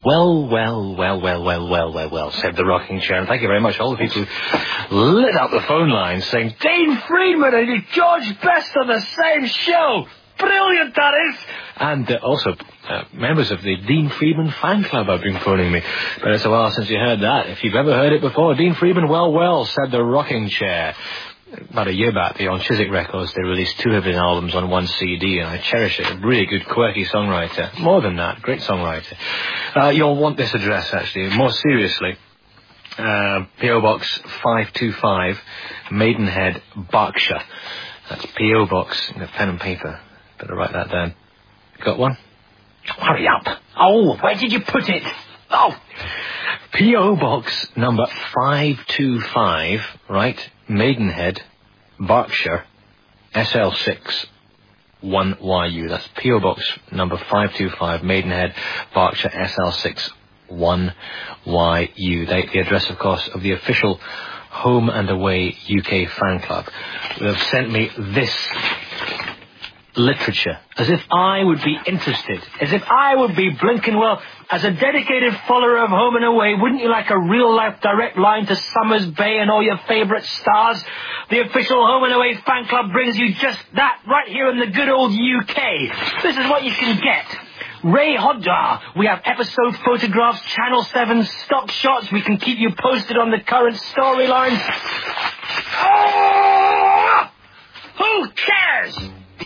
The interviews on this Site are all streaming Real Audio.